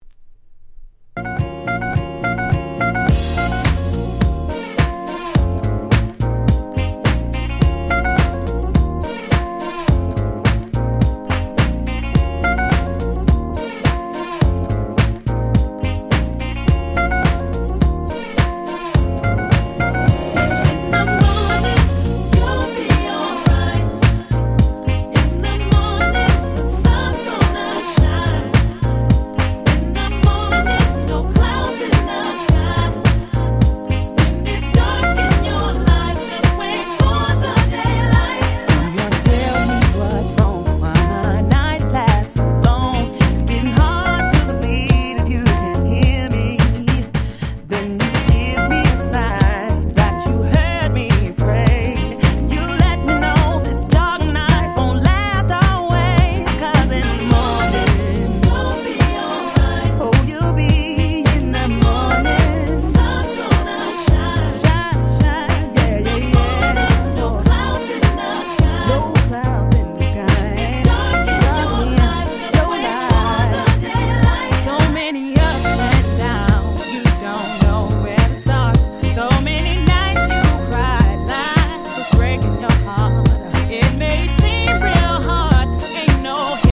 Nice Mash Up / Remix !!